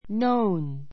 k nown 中 nóun ノ ウン （ ⦣ kn- で始まる語は k を発音しない） 動詞 know の過去分詞 形容詞 みんなに知られている , 周知の a known fact a known fact 誰 だれ もが知っている事実 one of the best known novelists in Japan one of the best known novelists in Japan 日本で最も著名な小説家のひとり